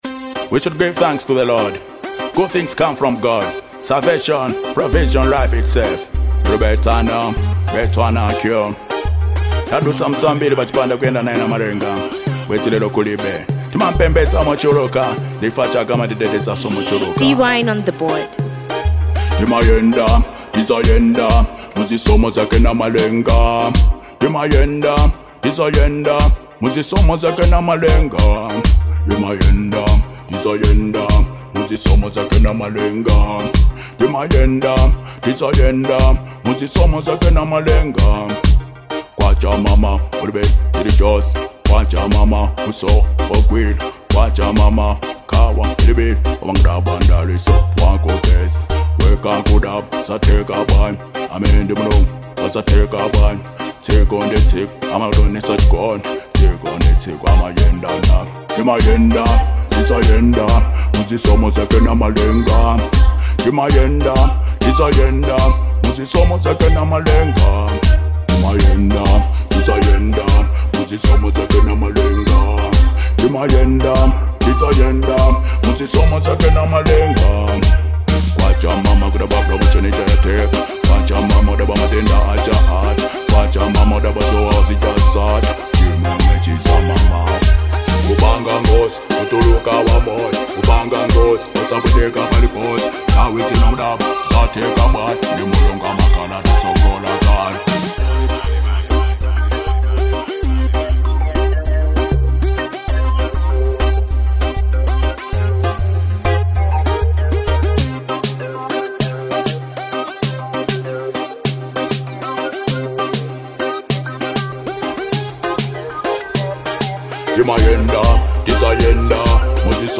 Genre : Reggae